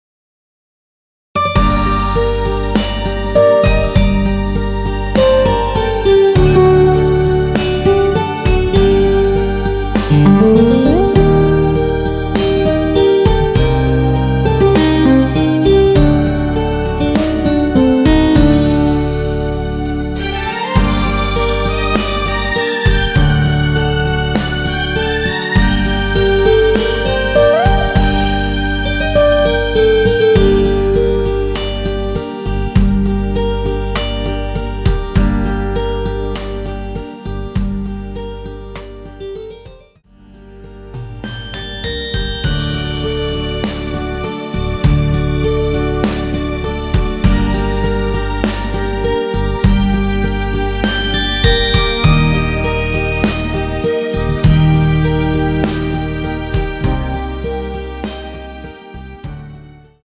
원키에서(+5)올린 MR입니다.
Gm
앞부분30초, 뒷부분30초씩 편집해서 올려 드리고 있습니다.
중간에 음이 끈어지고 다시 나오는 이유는